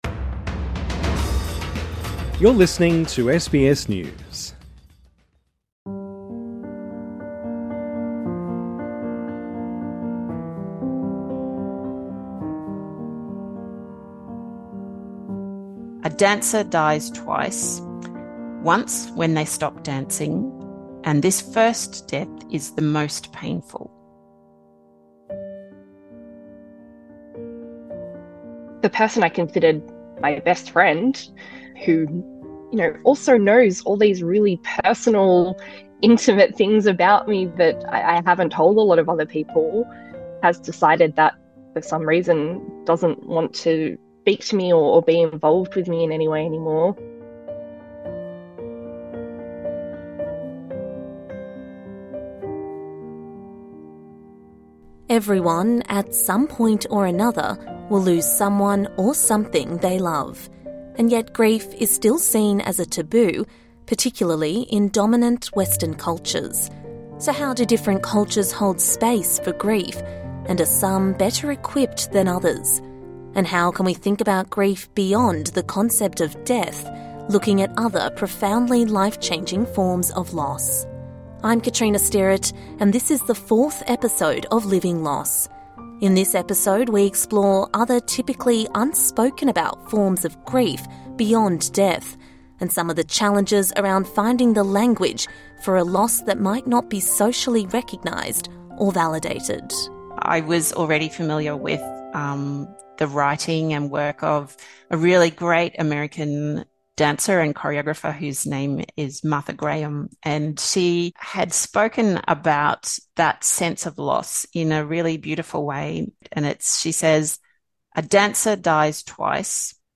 When we think of grief we think of death, but loss can come in a variety of sometimes unexpected forms. In this episode we hear a diverse range of stories from people who have struggled to find the language to communicate their grief.